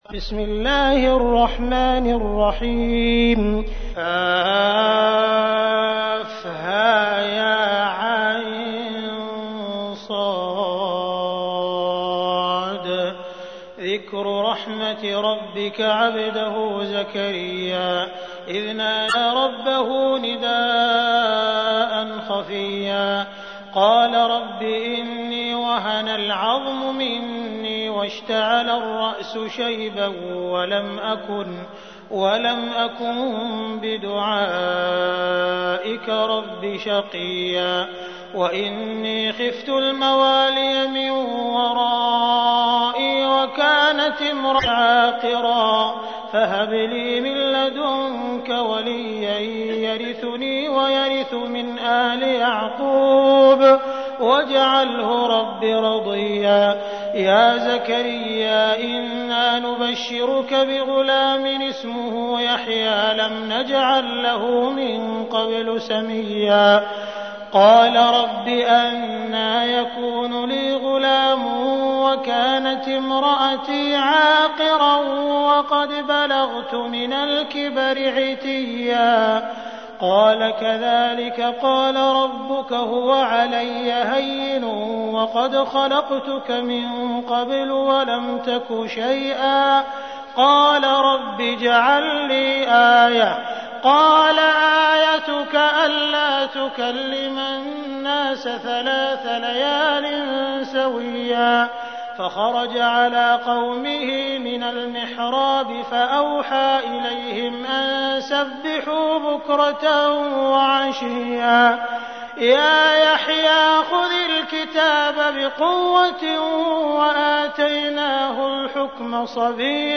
تحميل : 19. سورة مريم / القارئ عبد الرحمن السديس / القرآن الكريم / موقع يا حسين